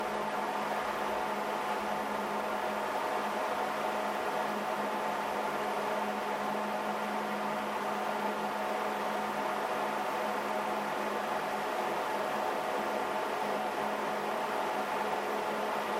At 75% speed, the highest frequency is 800 Hz, and there is a notable also spike at 200 Hz.
I have recorded the signals shown above, but please keep in mind that I’ve enabled Automatic Gain Control (AGC) to do so to make it easier for you to reproduce them.
75% Fan Speed